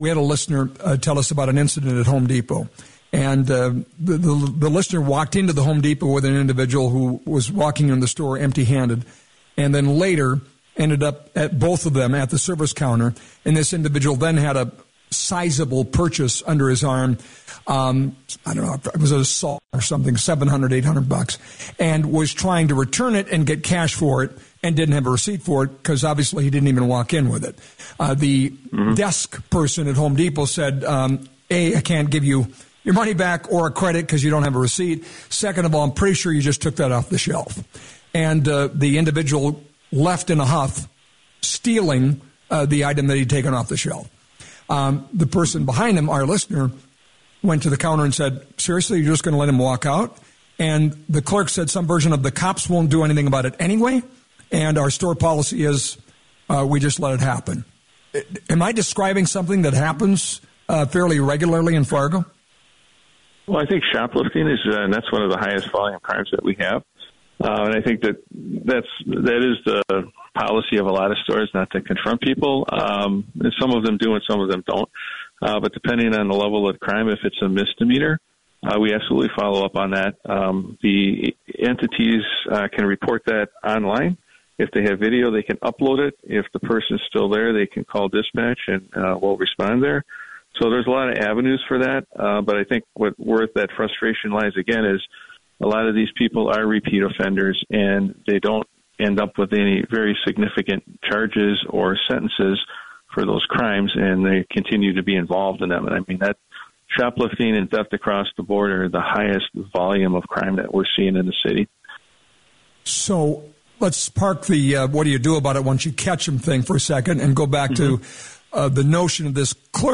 Chief David Zibolski was asked about the matter during an appearance on The Flag’s What’s On Your Mind?